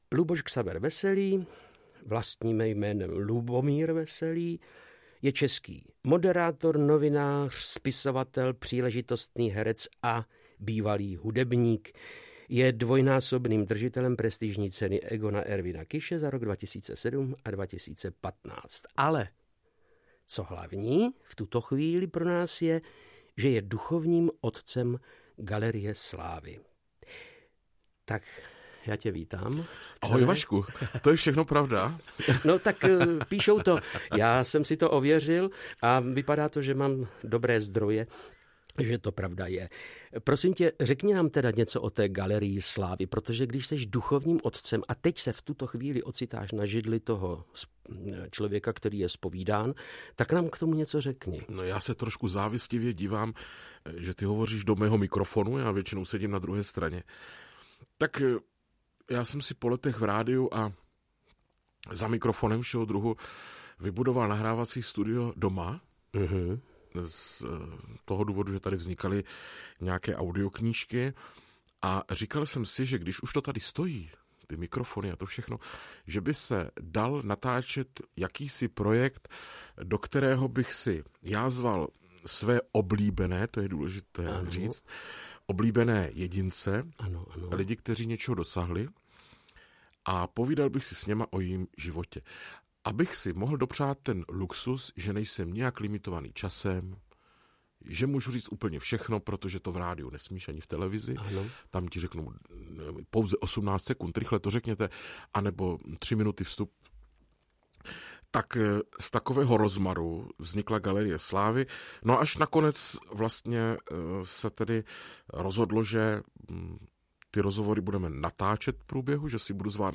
audiokniha obsahuje biografický rozhovor se známou osobností, který moderuje tentokrát Václav Knop
Ukázka z knihy
Audio kniha